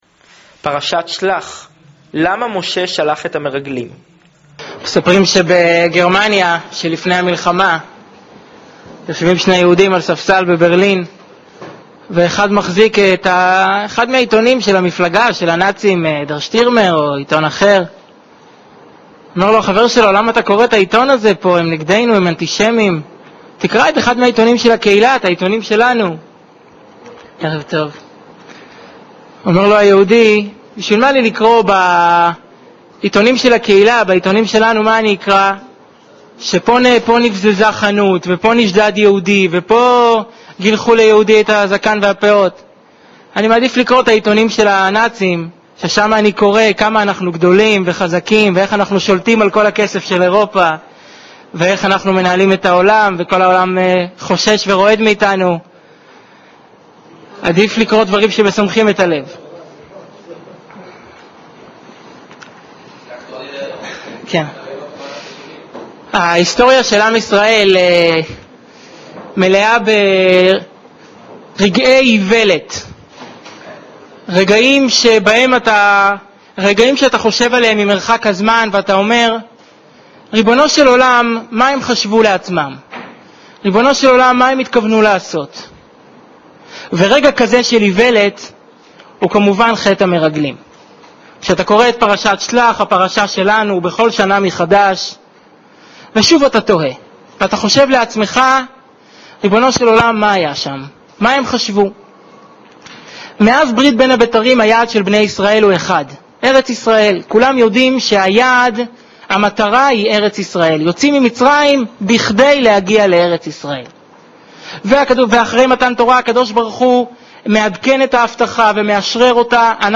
שיעור מרתק לפרשת שלח